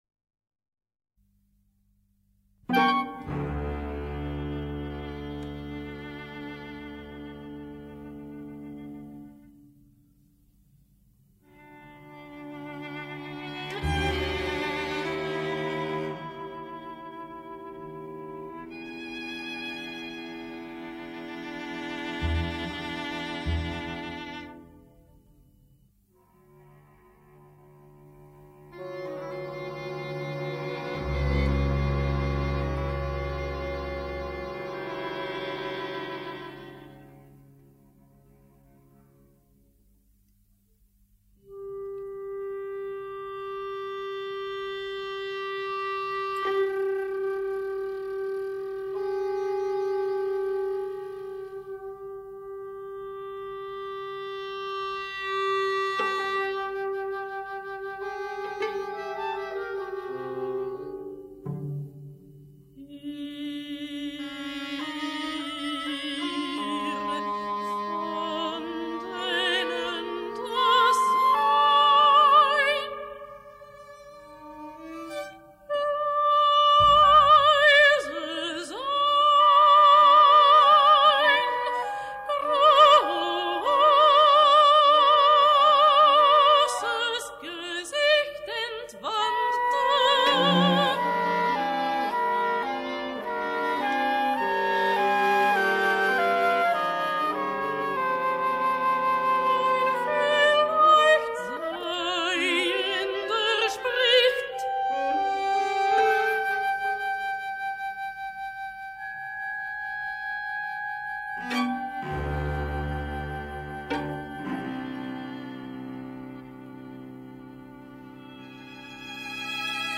soprano.